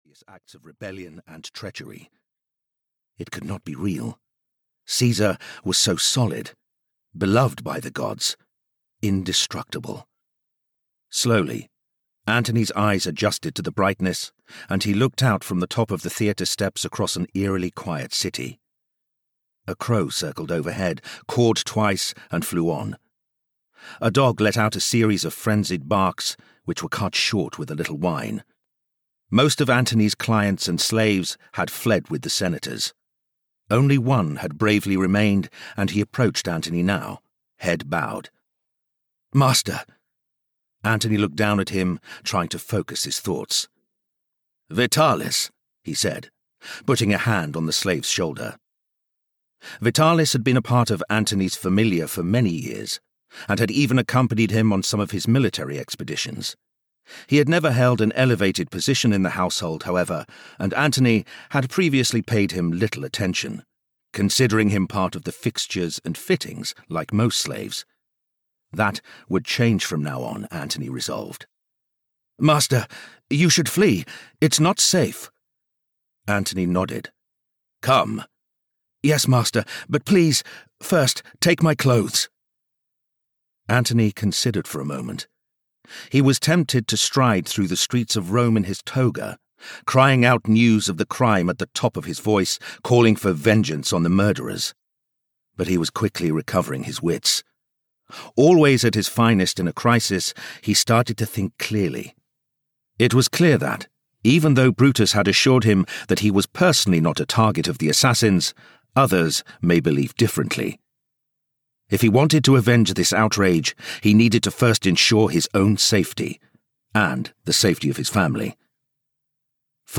Caesar's Avenger (EN) audiokniha
Ukázka z knihy